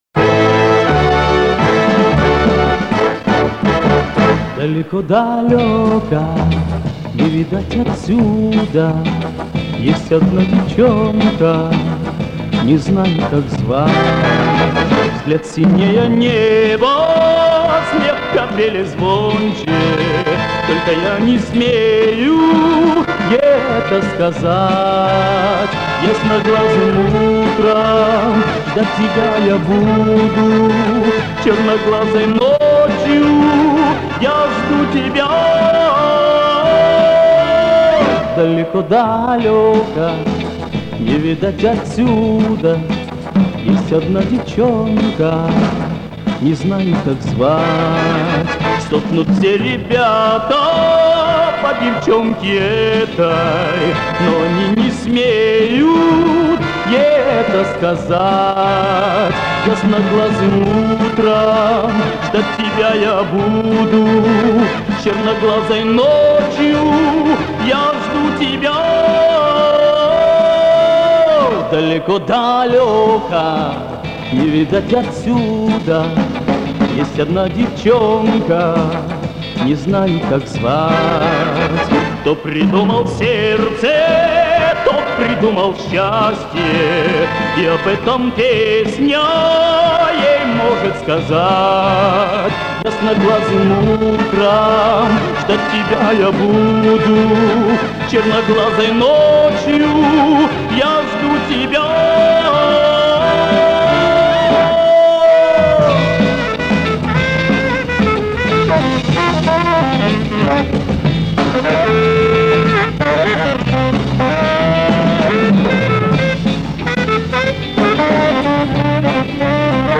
микро провалы звука